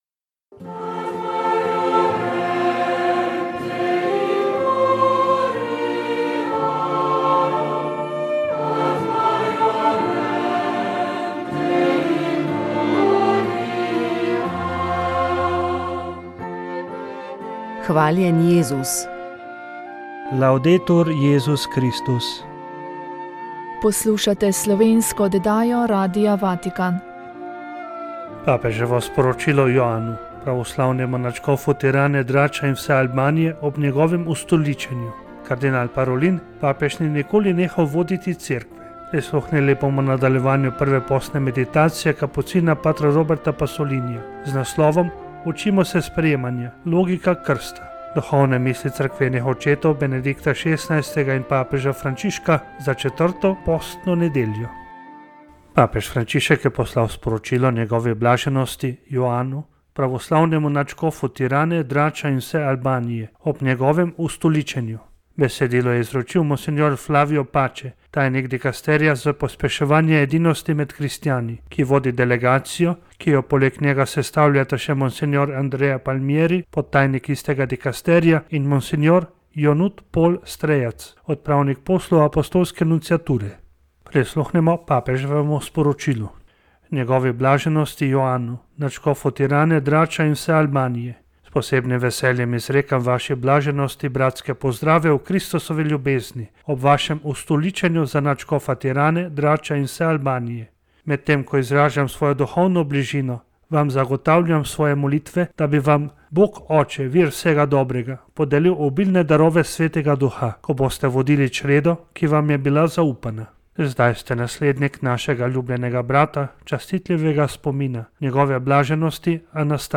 Krajši misijonski nagovor